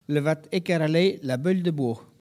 Saint-Hilaire-de-Riez
Catégorie Locution